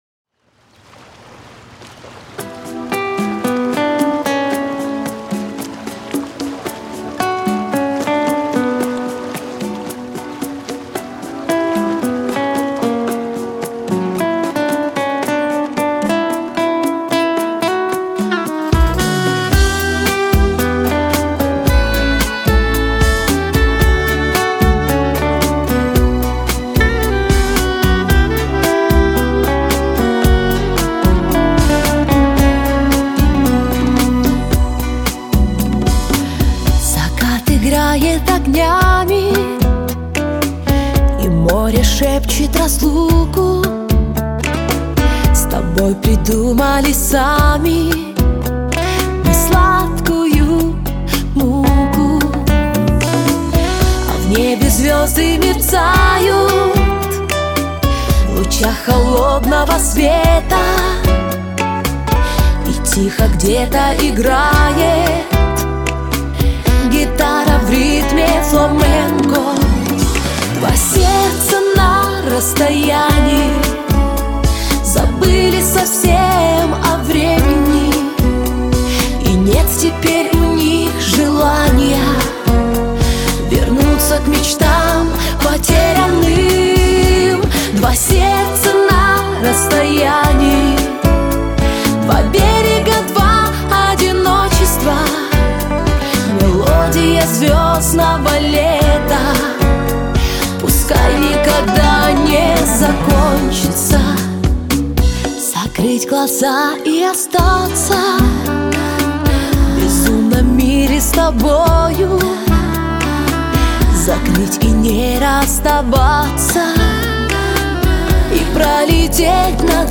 музыка попса